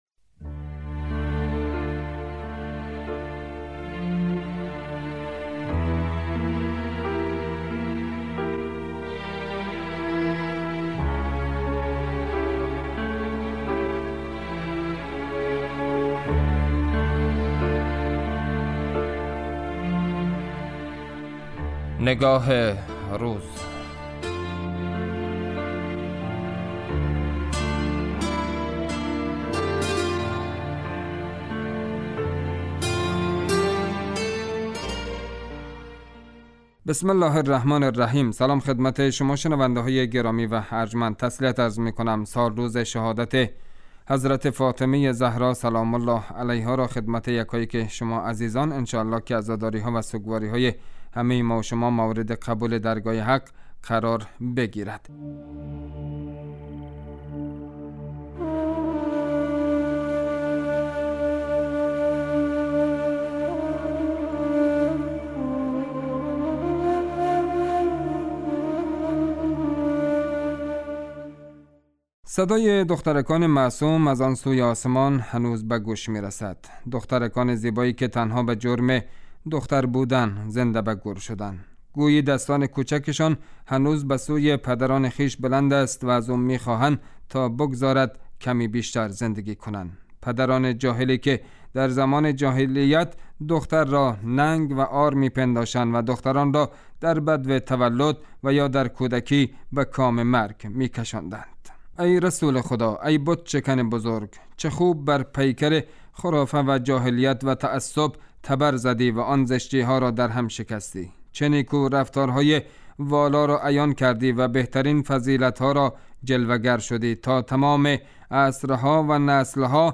اطلاع رسانی و تحلیل و تبیین رویدادها و مناسبتهای مهم ، رویکرد اصلی برنامه نگاه روز است .